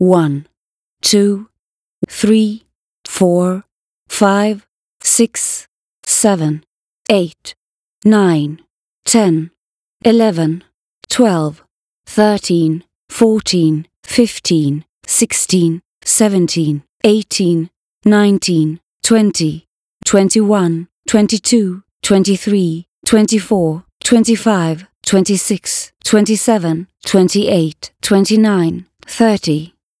> The audiofile does have S16LE/48kHz/mono, so there shouldn't be any format
count.wav